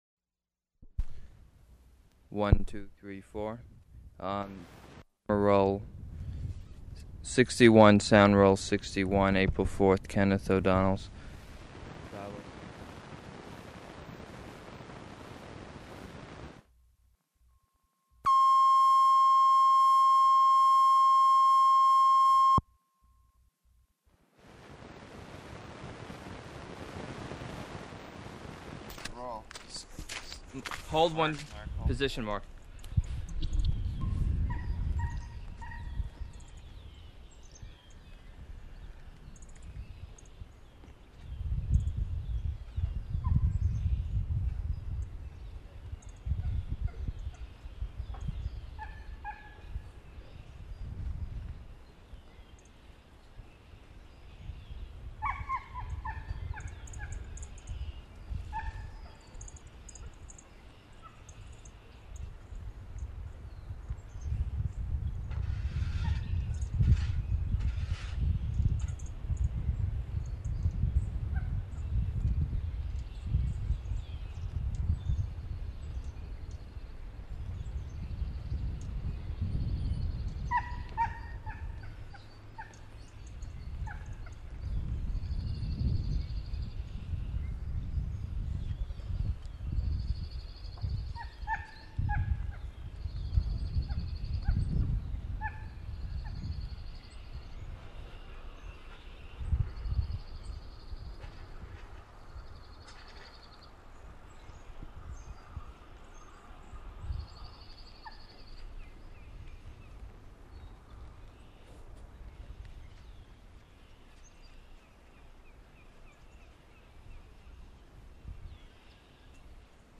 Ambient: birds, wind, cow
water, horses, sugar arch opening, sugar arch closing.
Format 1 sound tape reel (Scotch 3M 208 polyester) : analog ; 7 1/2 ips, full track, mono.